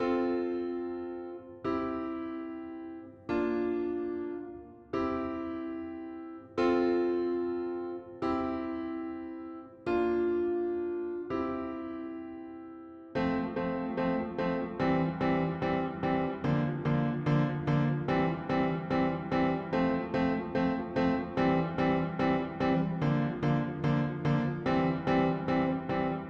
原声钢琴146bpm Fmajor Hi
Tag: 146 bpm Pop Loops Piano Loops 4.42 MB wav Key : F